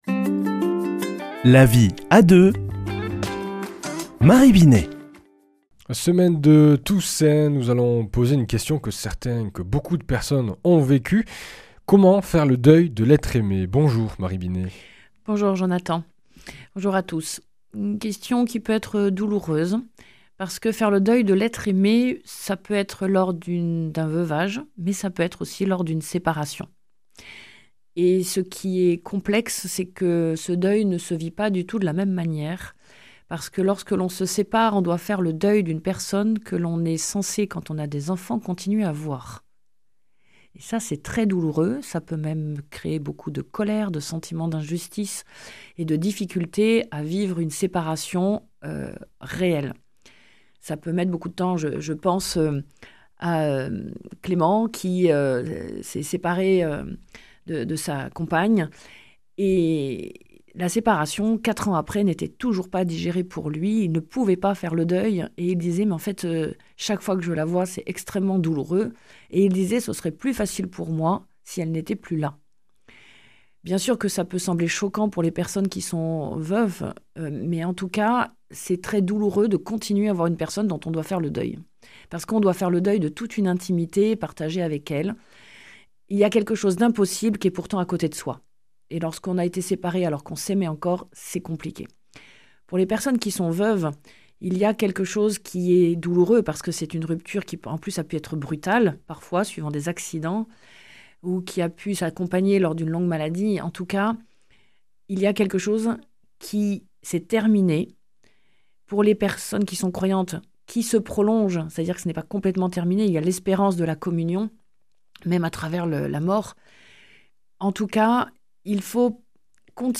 mardi 29 octobre 2024 Chronique La vie à deux Durée 4 min
Une émission présentée par